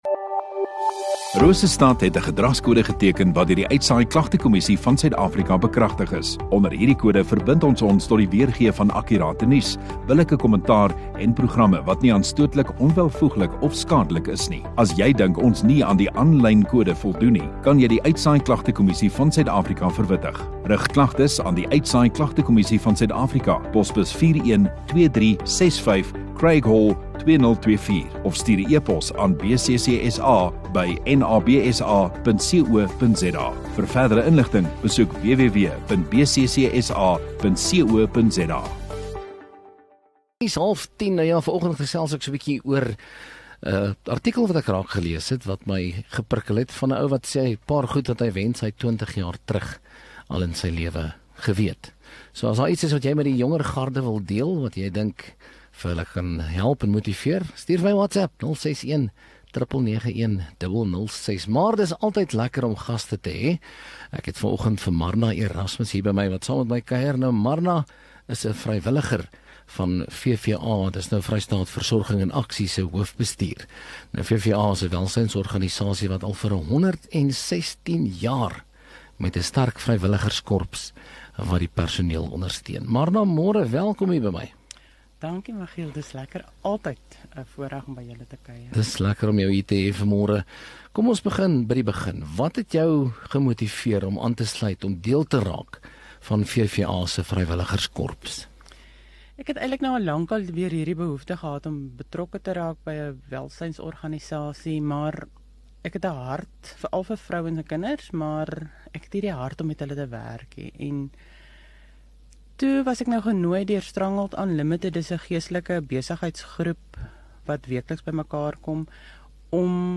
View Promo Continue Radio Rosestad Install Gemeenskap Onderhoude 11 Feb VVA Vrystaat nasorgsentrum